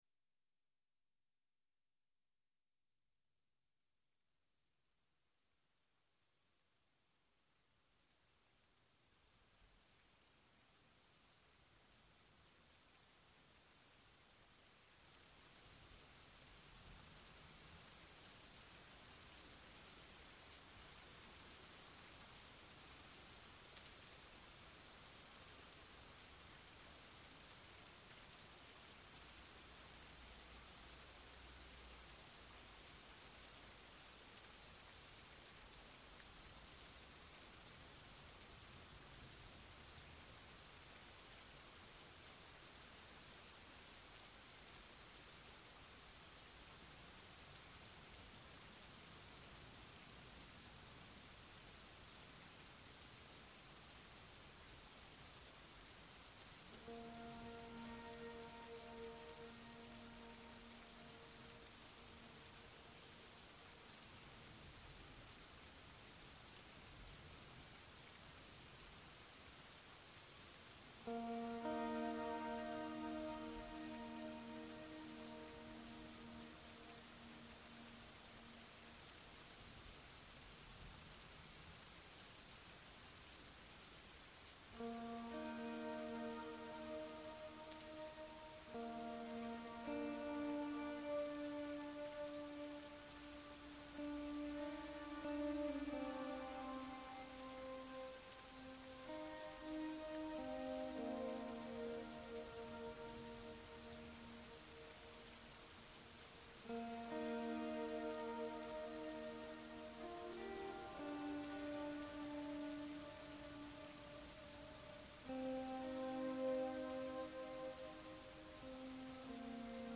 Just a little sleep aid audio with rain, no talking and quiet dreamy guitar textures.